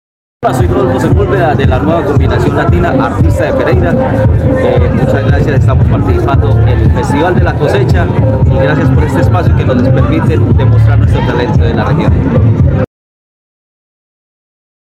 Cuba vivió el Festival de la Cosecha, una jornada llena de música y danza en el parque Guadalupe Zapata, hasta donde llegaron por lo menos 1000 personas quienes disfrutaron de música y danza con artistas de la ciudad.